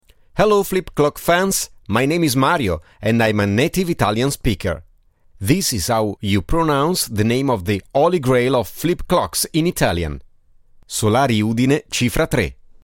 Collectors of flip clocks world wide may run in to a little difficulty in trying to pronounce the name of the "Holy Grail of Flip Clocks" - the Solari Udine Cifra 3. In an effort to help us out, a native Italian speaker was hired (see below).
How to properly pronounce Solari Undine Cifra 3
The pronunciation is something like: "So-lar-ee Oo-deen-ay Chee-fra Tray"